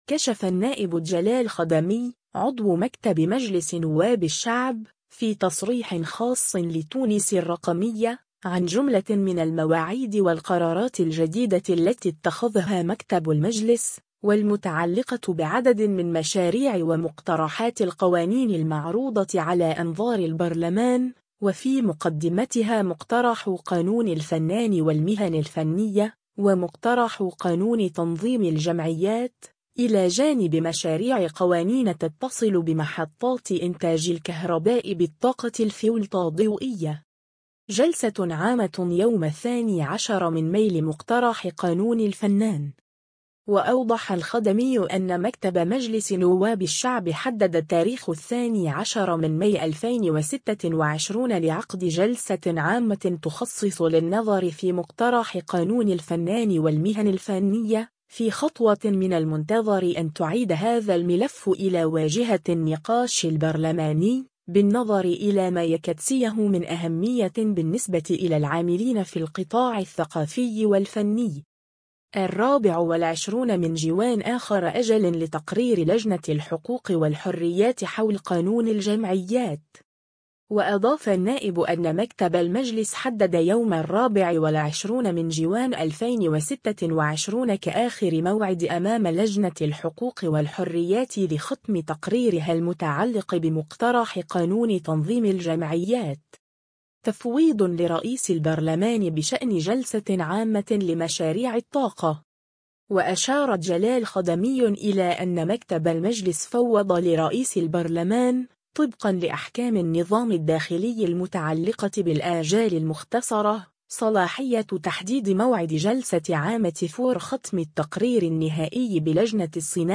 كشف النائب جلال خدمي، عضو مكتب مجلس نواب الشعب، في تصريح خاص لـ”تونس الرقمية”، عن جملة من المواعيد والقرارات الجديدة التي اتخذها مكتب المجلس، والمتعلقة بعدد من مشاريع ومقترحات القوانين المعروضة على أنظار البرلمان، وفي مقدمتها مقترح قانون الفنان والمهن الفنية، ومقترح قانون تنظيم الجمعيات، إلى جانب مشاريع قوانين تتصل بمحطات إنتاج الكهرباء بالطاقة الفولطاضوئية.